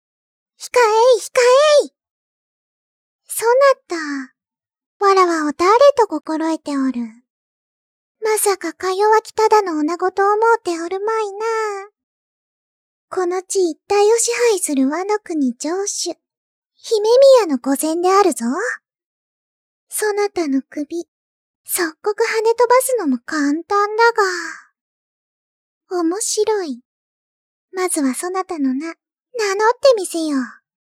位の高いちょっと嫌味っぽい女の子（少女声）